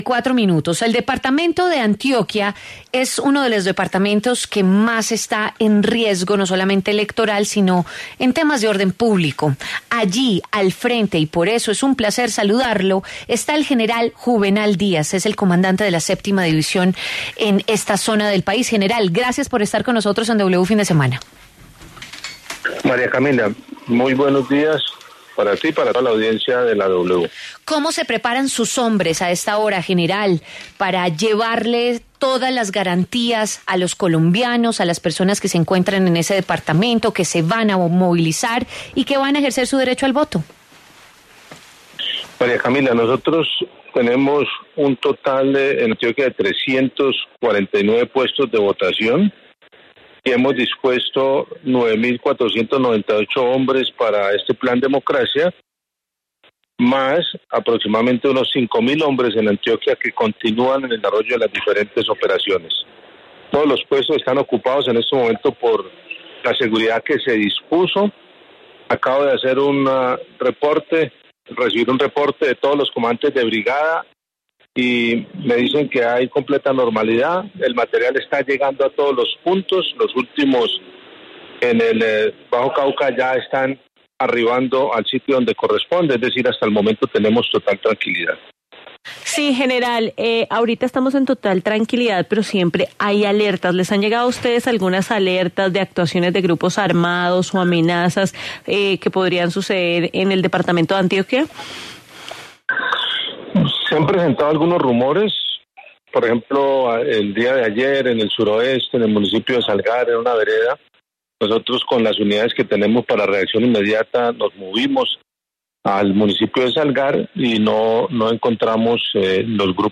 El general Juvenal Díaz, comandante de la Séptima División en Antioquia, habló en W Fin De Semana sobre las garantías que se darán por parte de las autoridades durante la jornada electoral de este 29 de mayo.